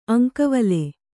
♪ aŋkavale